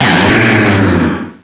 pokeemerald / sound / direct_sound_samples / cries / haxorus.aif